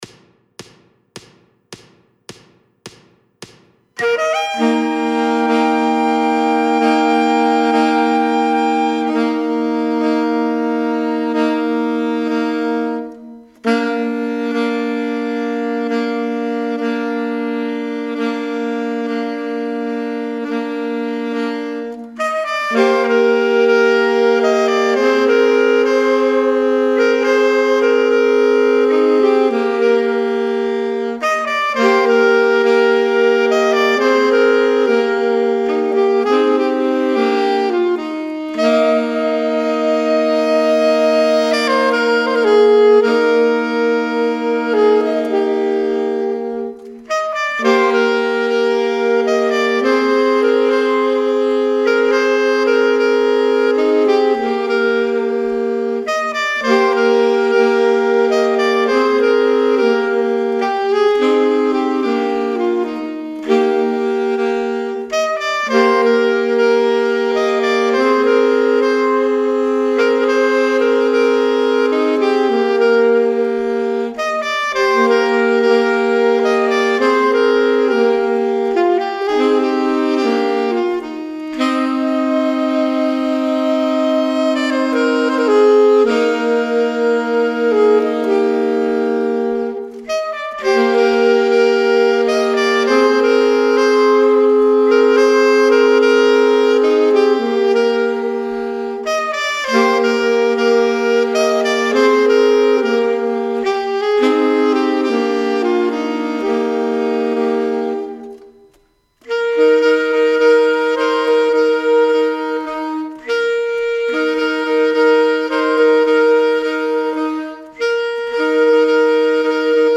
minus Ts/Bari Weiterlesen »